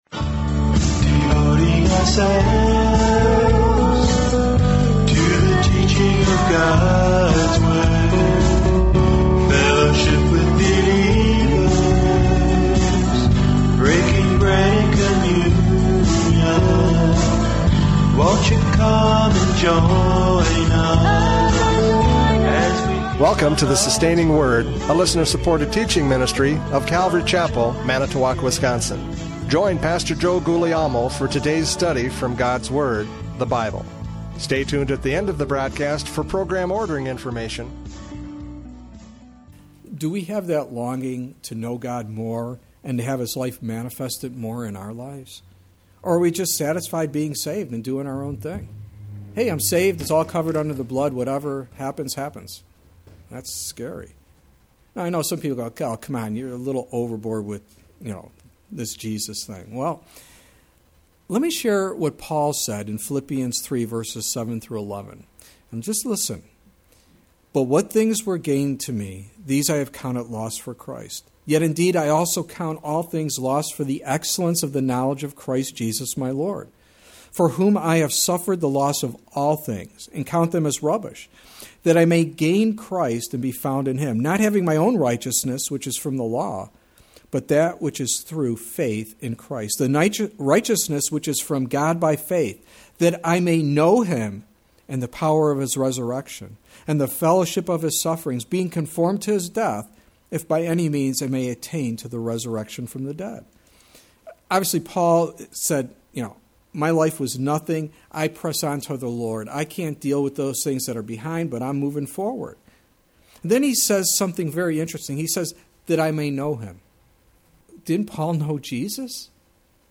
Judges 14:1-7 Service Type: Radio Programs « Judges 14:1-7 Down